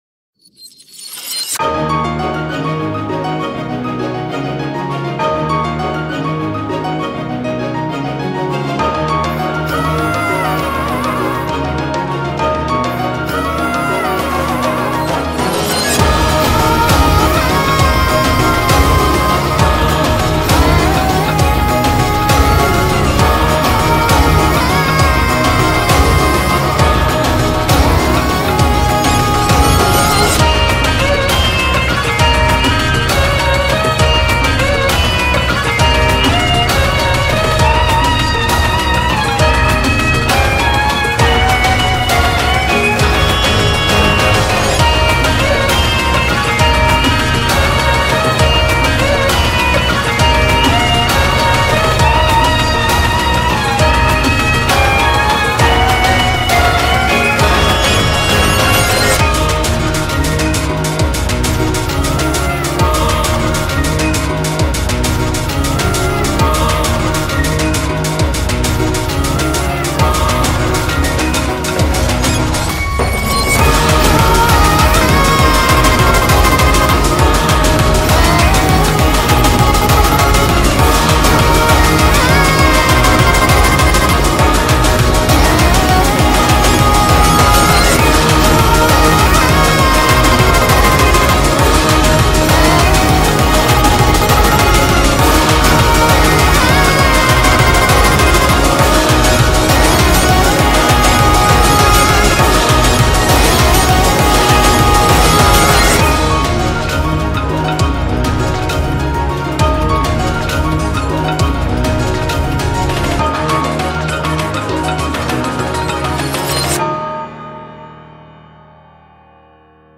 BPM200
Audio QualityPerfect (Low Quality)